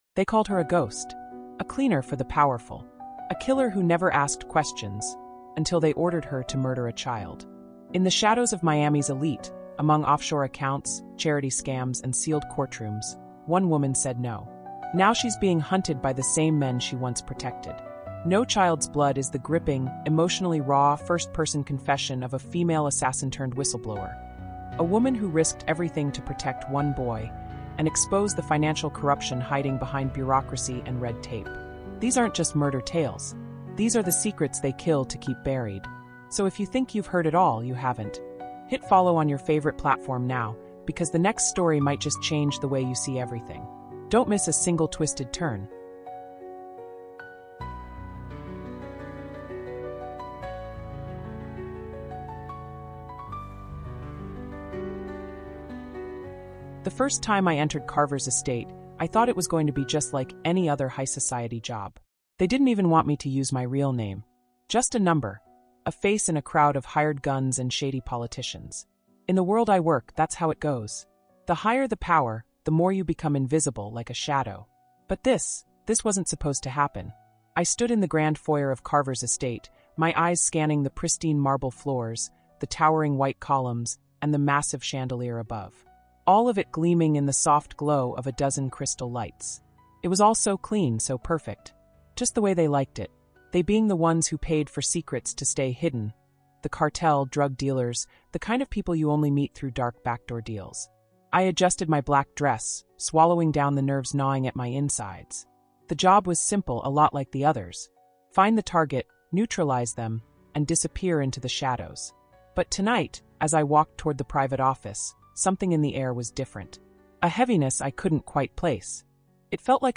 NO CHILD’S BLOOD is a haunting first-person true crime story that pulls back the curtain on a world of corruption, conspiracy, and dark history. Told from the perspective of a female assassin, this emotionally immersive crime investigation unfolds across eight chilling chapters as she reveals her work for powerful politicians, cartels, and billionaires — and the final job that broke her: a contract to kill a child.